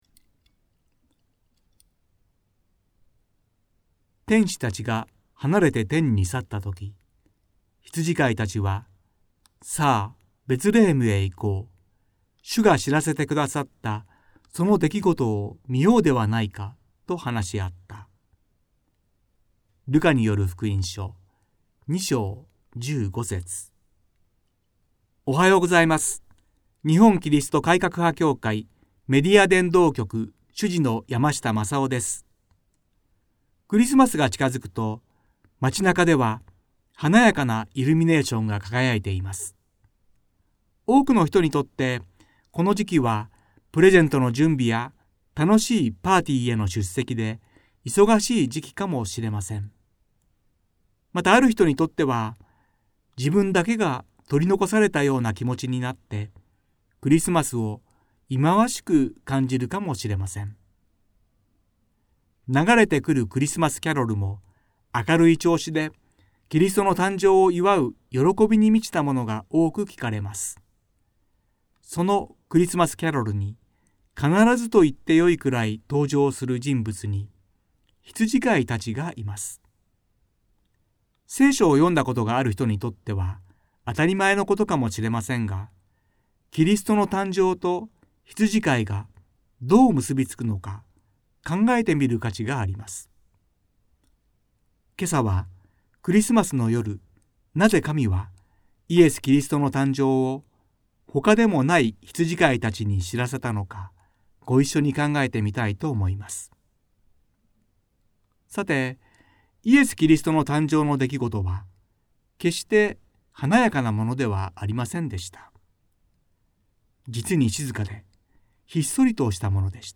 ラジオ番組「キリストへの時間」